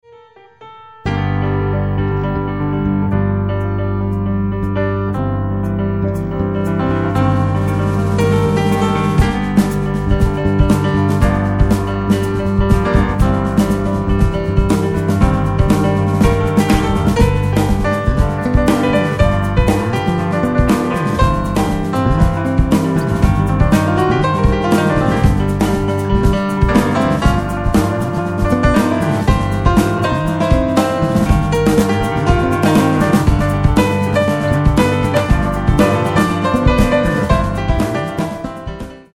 PIANO TRIO
甘く儚く、切なげに舞い上がるミラクルなメロディの応酬。